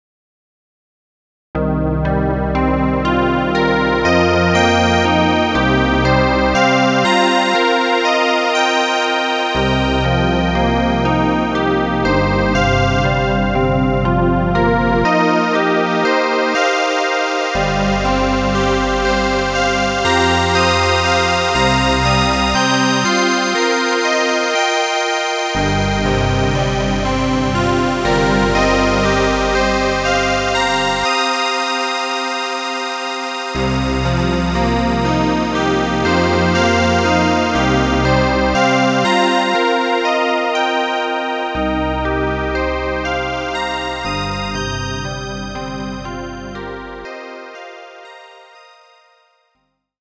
Cycle chords with reduced bits and samples
• Bit rate to 4bit
• Sample reduced by one-third